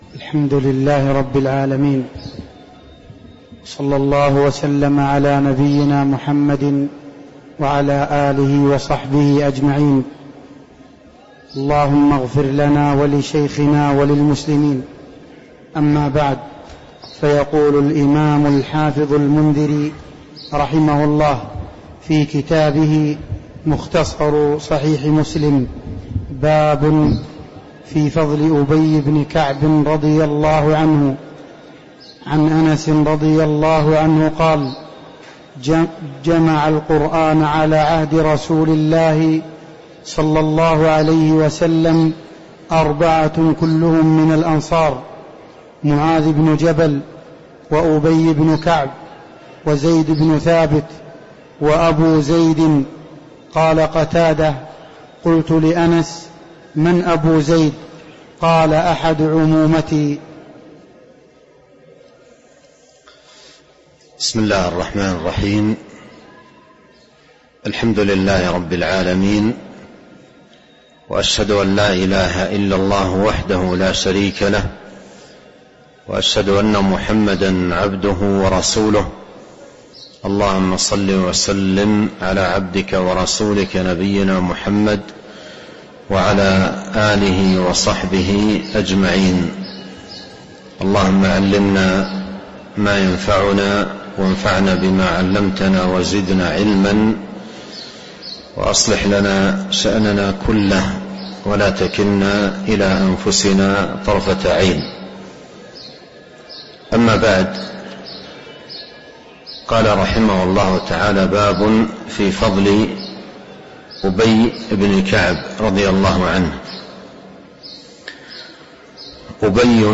تاريخ النشر ١٦ رمضان ١٤٤٣ هـ المكان: المسجد النبوي الشيخ